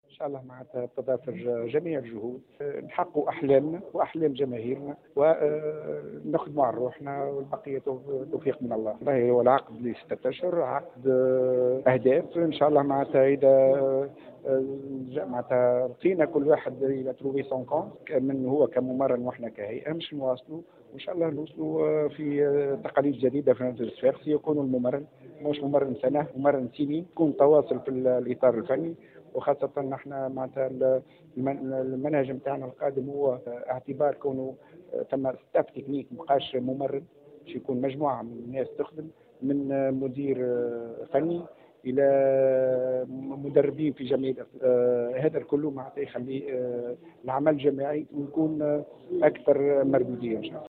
عقدت الهيئة المديرة للنادي الصفاقسي اليوم ندوة صحفية لتقديم المدرب الجديد للفريق الأرجنتيني السويسري ناستور كلاوزن الذي سيخلف المدرب لشهاب الليلي الذي تمت اقالته منذ شهرين.